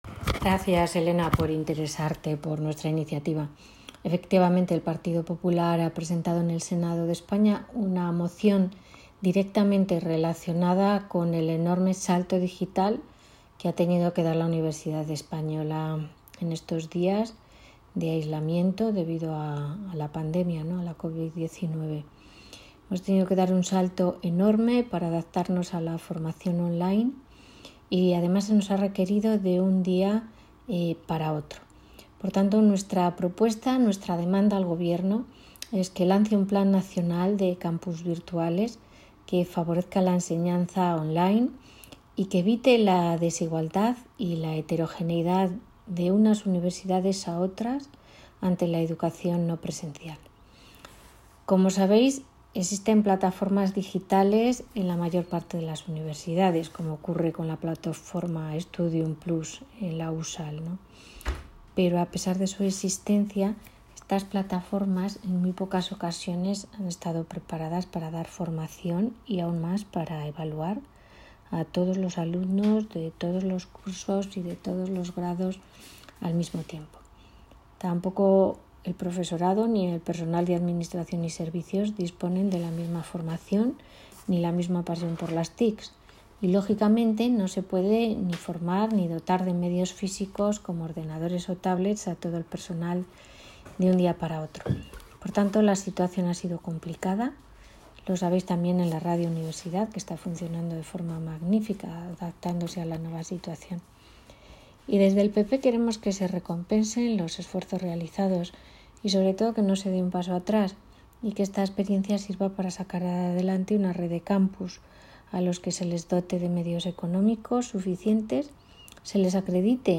La senadora, además de profesora de la USAL y directora del programa Kakebo en Radio USAL, nos comentaba la noticia esta mañana para la radio.